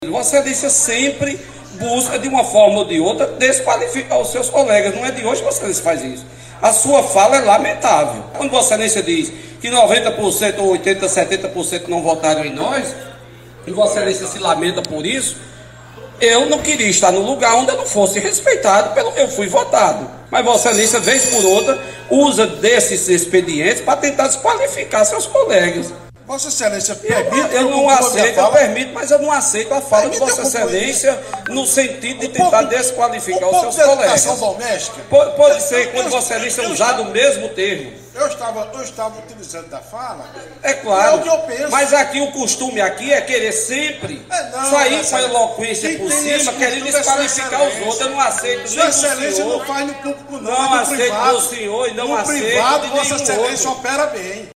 A Sessão Plenária realizada nesta quarta-feira (07/06) nas dependências da Câmara Municipal de Campina Grande (CMCG) foi marcada por um bate-boca protagonizado por vereadores da mesma legenda.
Os parlamentares Alexandre do Sindicato e Olímpio Oliveira, ambos do União Brasil, mas que são de bancadas distintas na Casa Legislativa, trocaram farpas e tumultuaram a Sessão do dia.
Os comentários do parlamentares foram registrados pelo programa Correio Debate, da 98 FM, nesta quarta-feira (07/06).